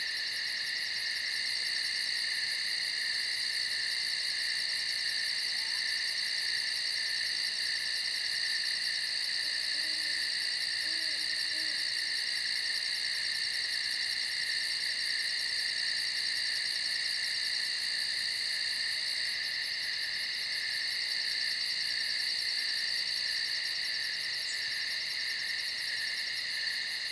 NOCHE DE GRILLOS
Tonos EFECTO DE SONIDO DE AMBIENTE de NOCHE DE GRILLOS
Noche_de_grillos.mp3